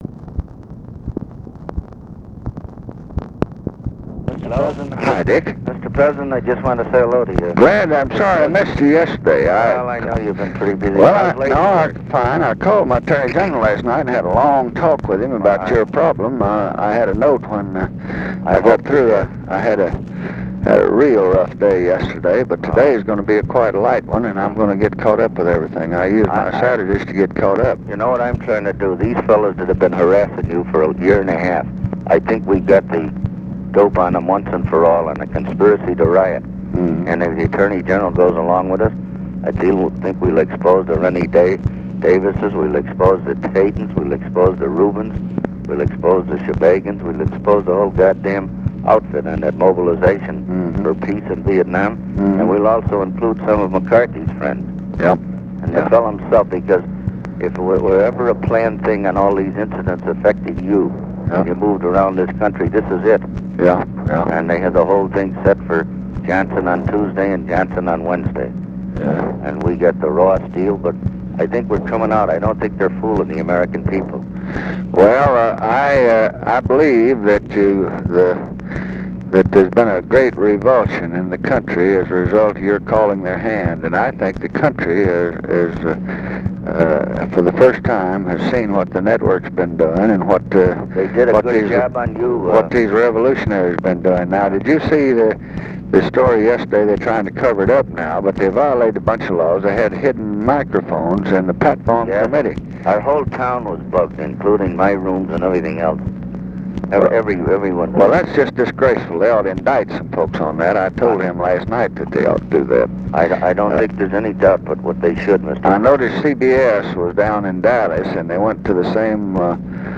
Conversation with RICHARD DALEY, September 7, 1968
Secret White House Tapes